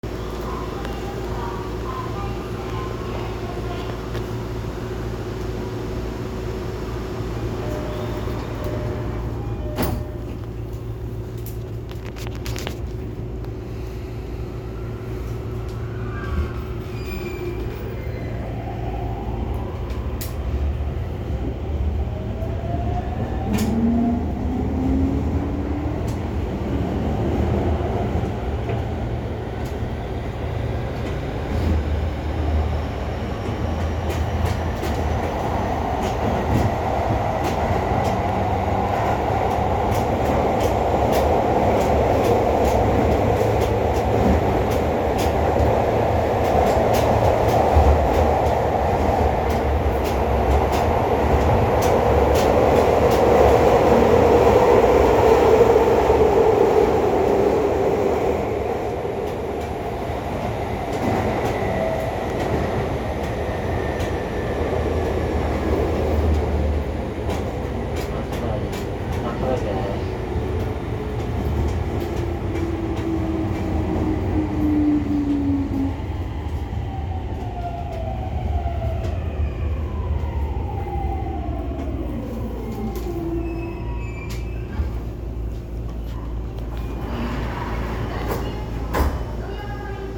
・10-300形（2次車まで）走行音
【京王新線】笹塚→幡ヶ谷
線路の幅を都営新宿線に合わせただけで、基本的にJR東日本のE231系(500番台etc)と全く同じ三菱IGBTです。ドアチャイムもJRタイプですが、若干音程が低くなっています。